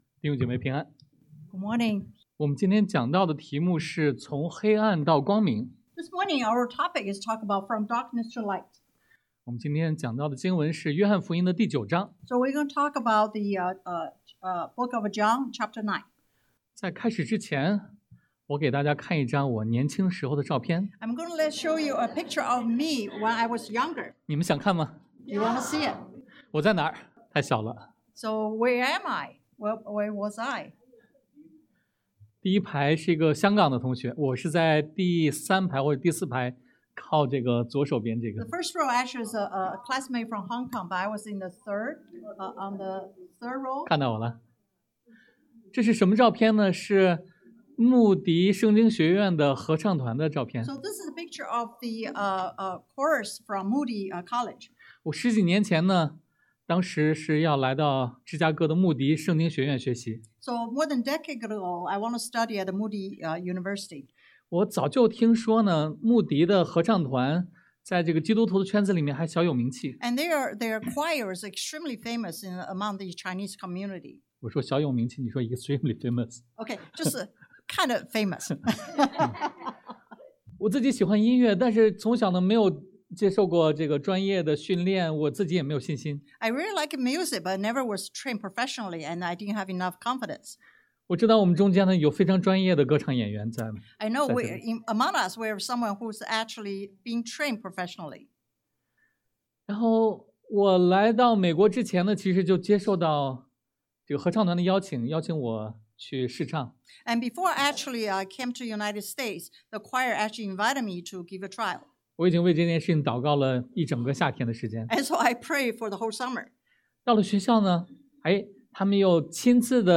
Passage: 约翰福音 John 9 Service Type: Sunday AM